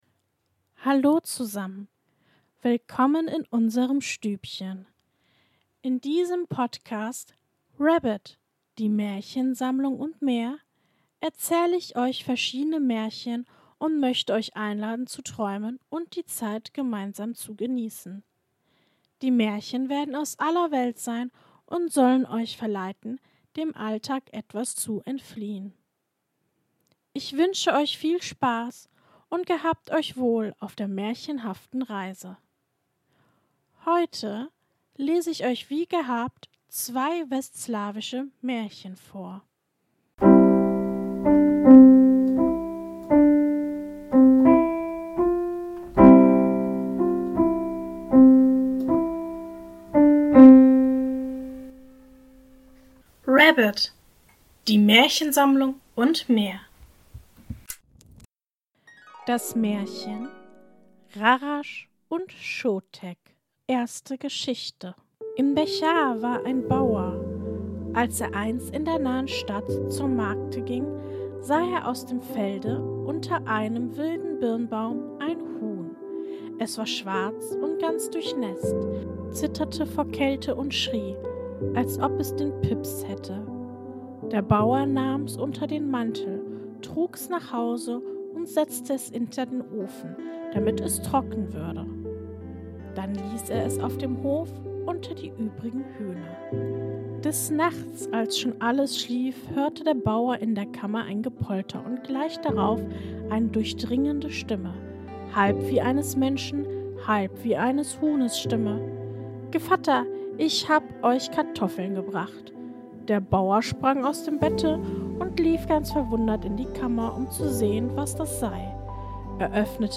In der heutigen Folge lese ich Folgendes vor: 1. Rarasch und Schotek. 2. Die Waldfrau.